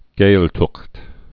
(gāltət)